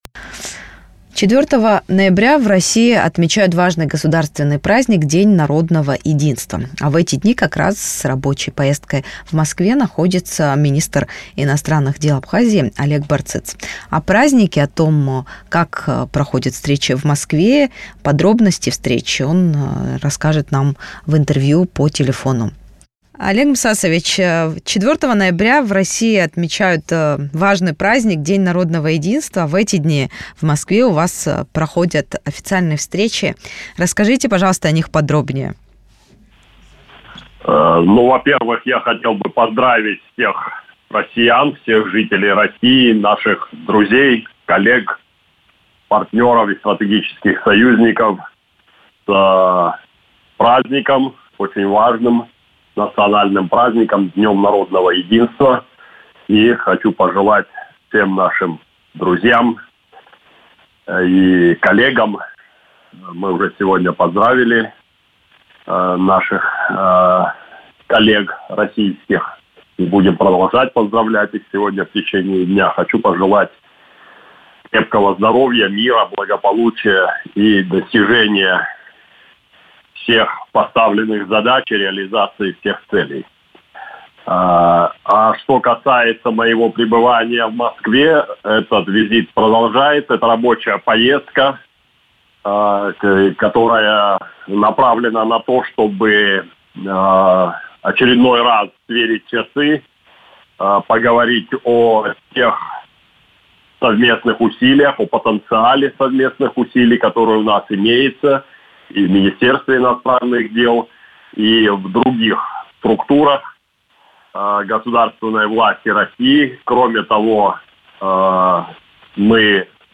Сверка часов: интервью главы МИД Абхазии в День народного единства России